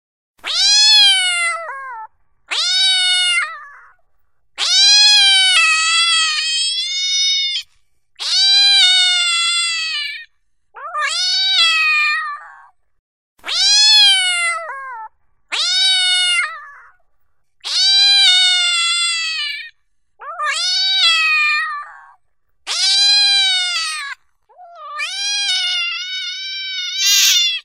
怒った猫の鳴き声 着信音
この猫はかなり怒っています。けれども猫の声ってどの声も可愛らしく聞こえるよね。